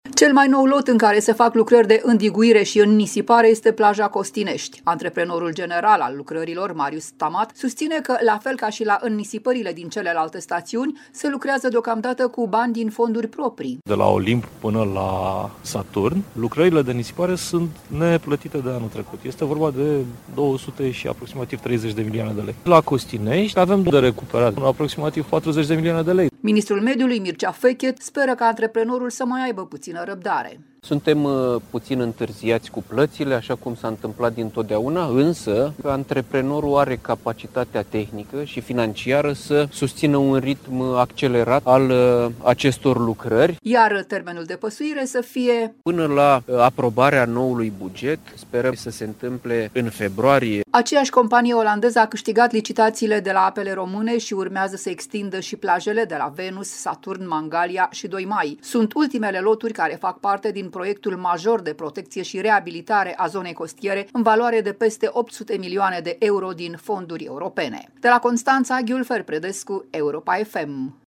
Ministrul Mediului, Mircea Fechet: Sperăm ca noul buget să fie aprobat în februarie